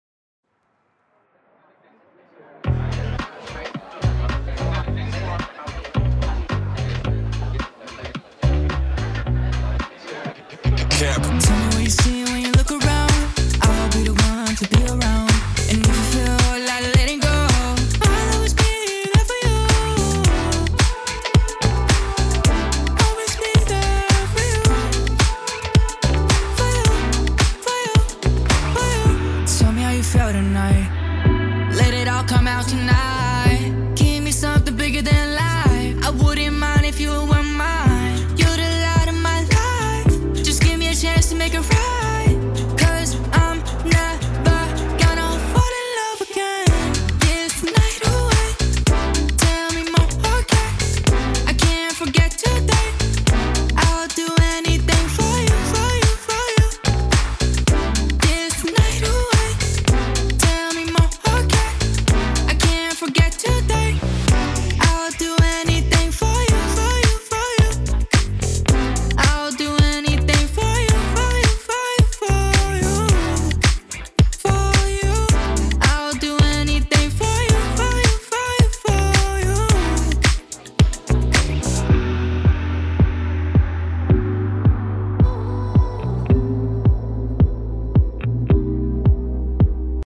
The microphone used was the SM7B running into an SSL 4000 E Channel Strip Pre Other than that any feedback would be great only looking for mix and mastering advice Just a Demo...